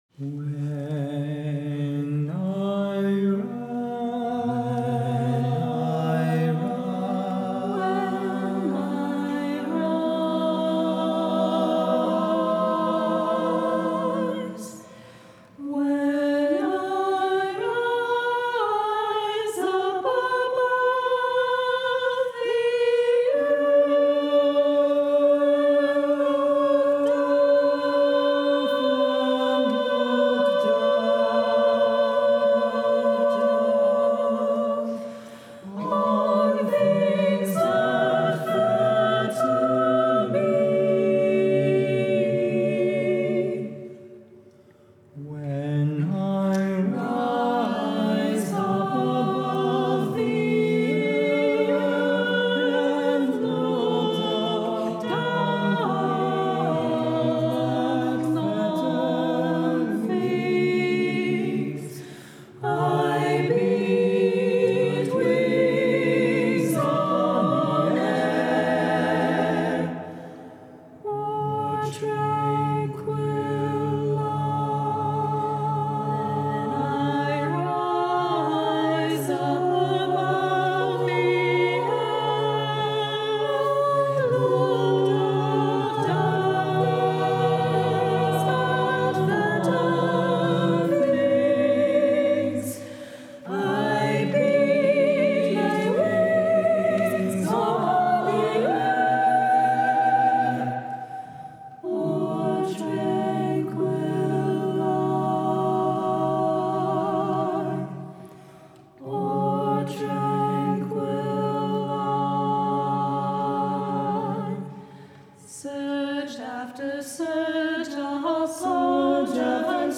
for chorus